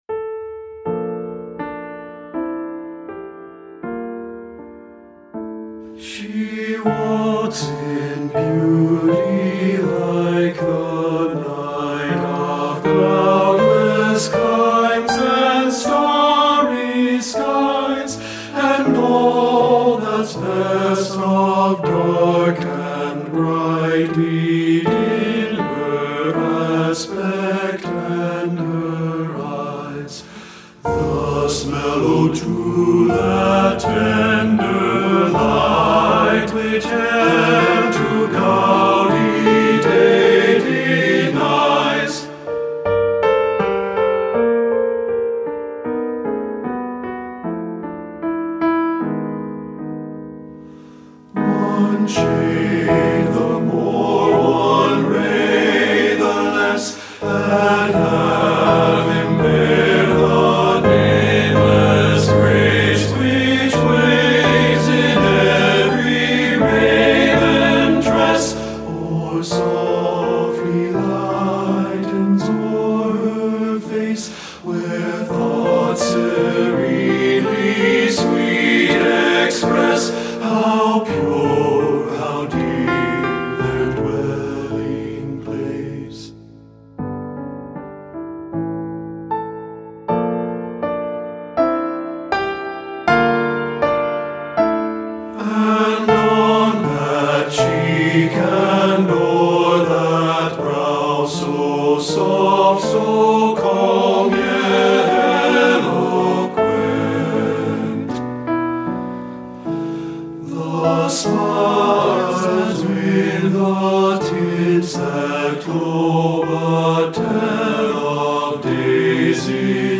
Voicing: TTB and Piano